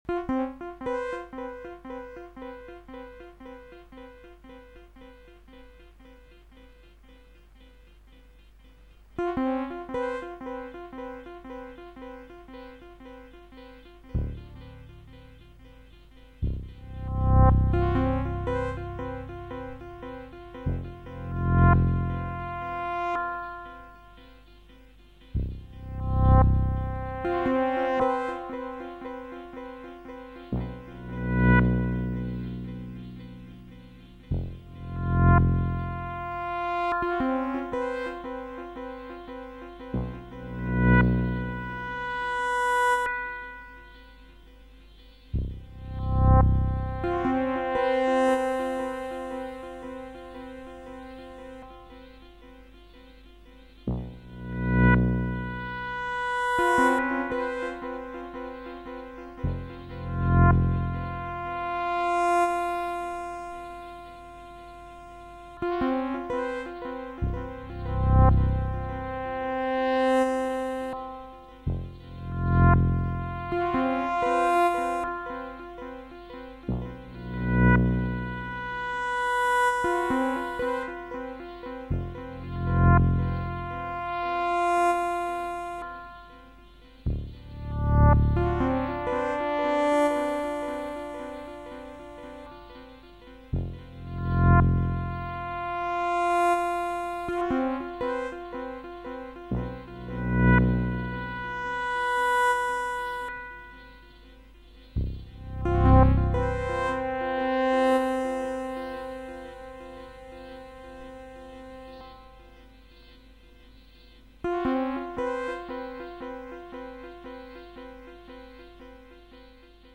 Format: Reel to Reel